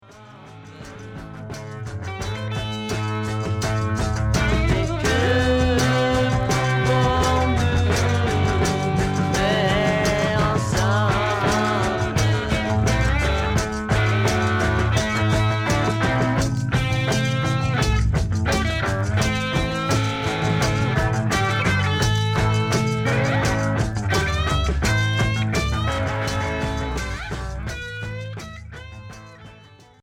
Progressif Deuxième 45t retour à l'accueil